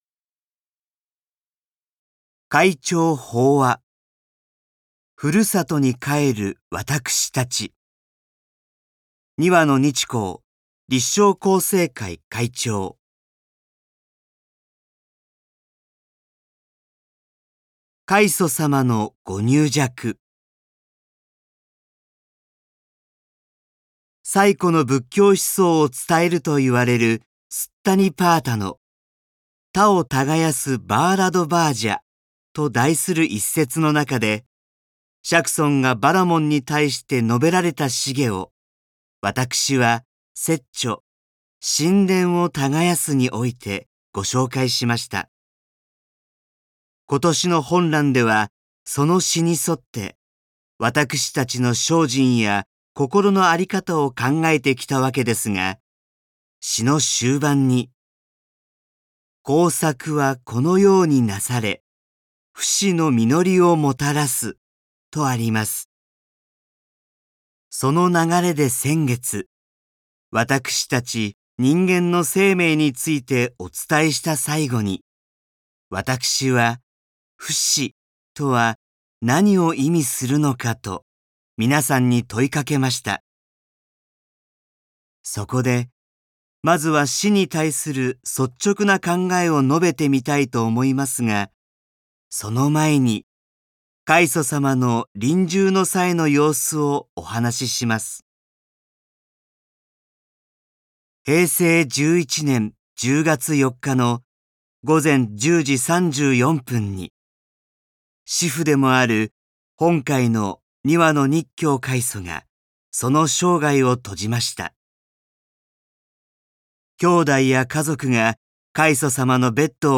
朗読MP3 podcast